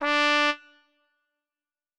Cow_Life_Sim_RPG/Sounds/SFX/Instruments/Trumpets/doot2.wav at e69d4da15373a101a490e516c925cbcdf63458a3